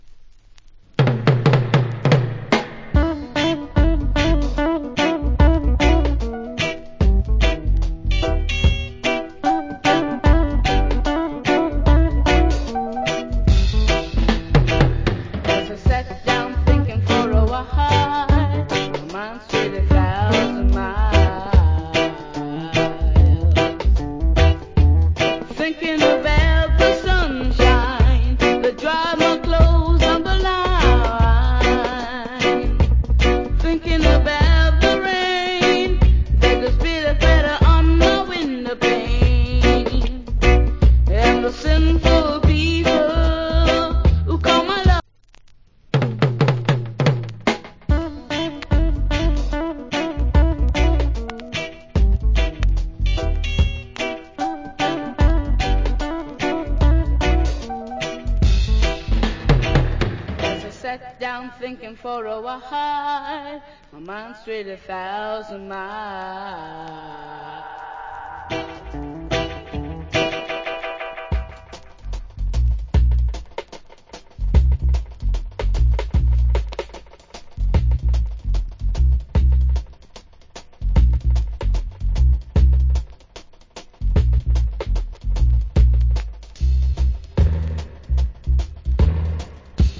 Wicked Roots Rock Vocal.